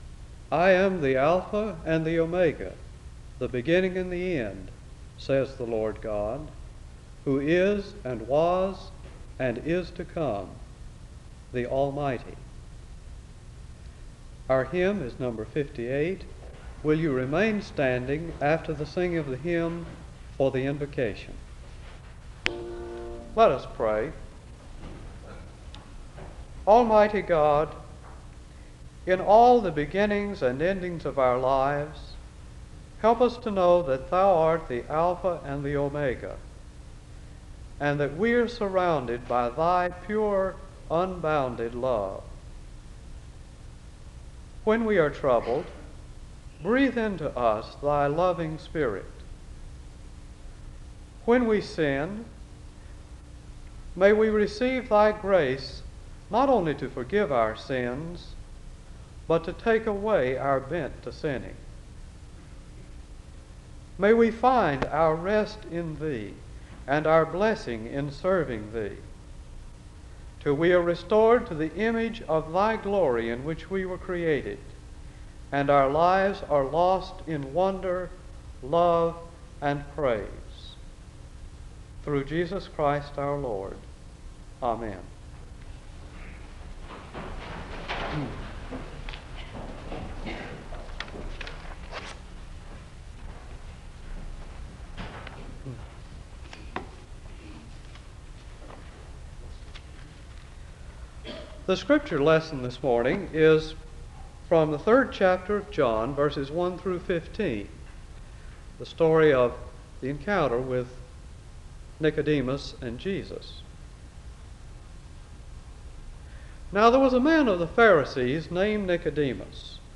The service begins with a word of prayer (00:00-01:25).
SEBTS Chapel and Special Event Recordings